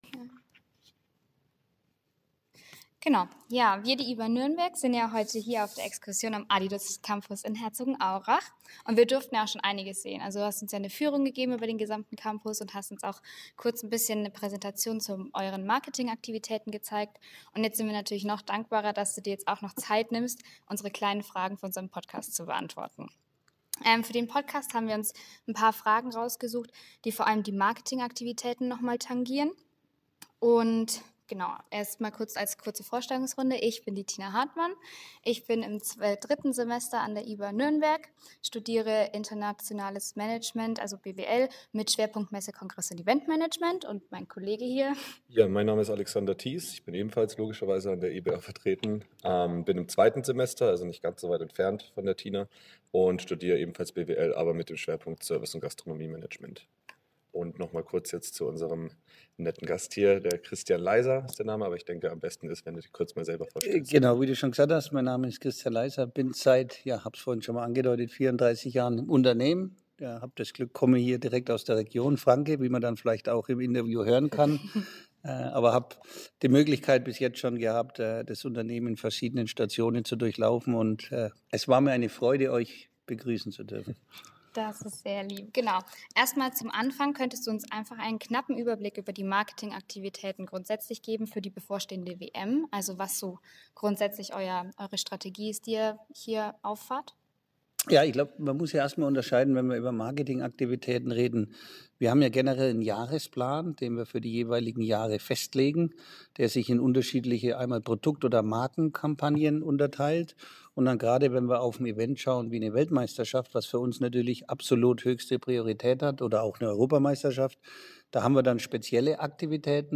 Das aufschlussreiche und informative Gespräch wurde aufgezeichnet und ist nun als Podcast verfügbar.